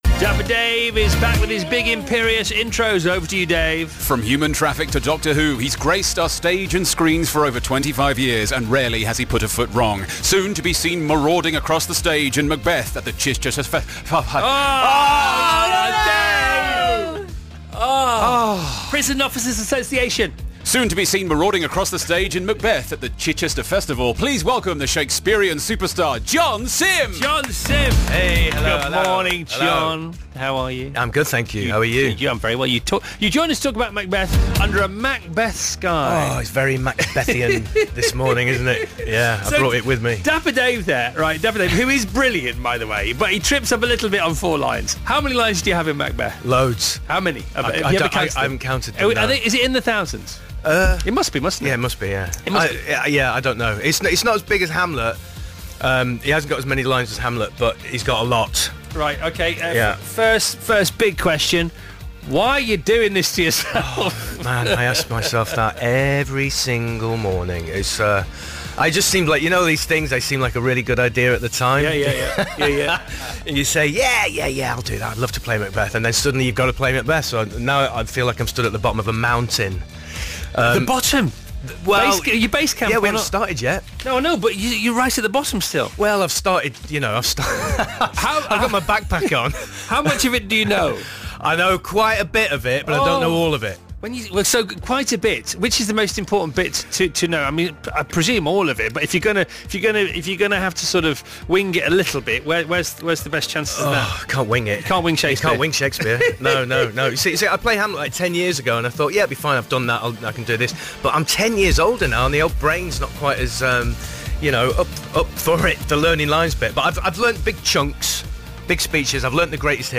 Radio Interview: Chris Evans Chats with BAFTA-Nominated Acting Royalty John Simm